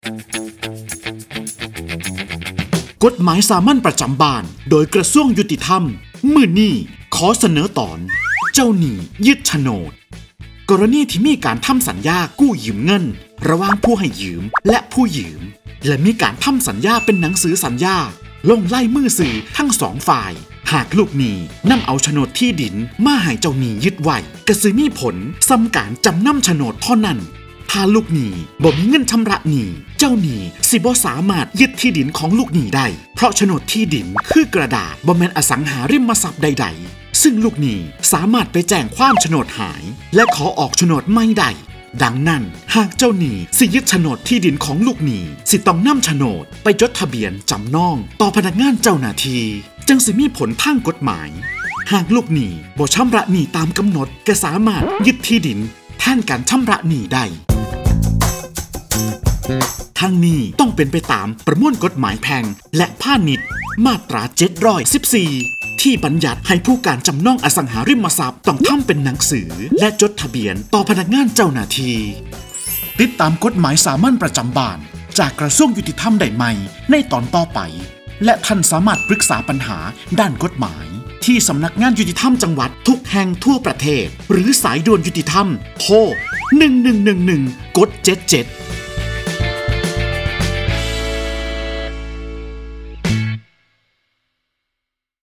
กฎหมายสามัญประจำบ้าน ฉบับภาษาท้องถิ่น ภาคอีสาน ตอนเจ้าหนี้ยึดโฉนด
ลักษณะของสื่อ :   คลิปเสียง, บรรยาย